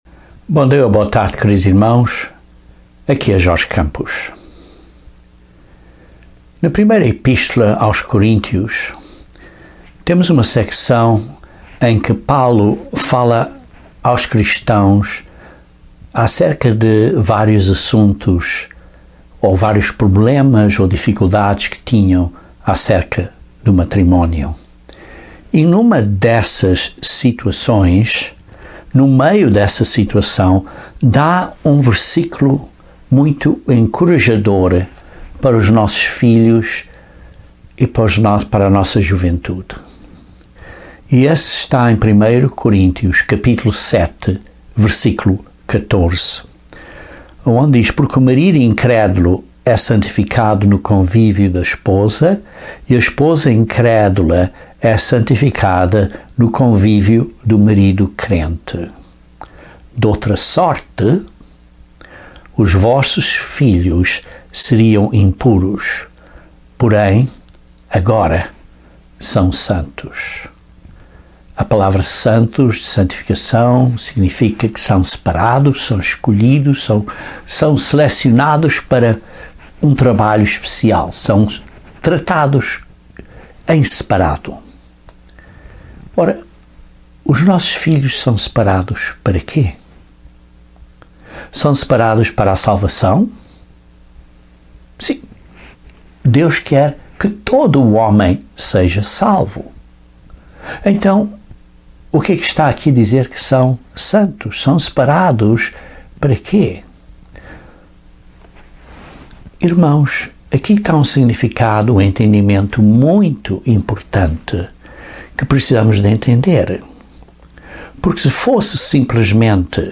Deus nos chama nesta era para sermos treinados para responsibilidades muito importantes no Reino de Deus à segunda vinda de Cristo. Este sermão aborda a necessidade de entendermos a importância deste treinamento.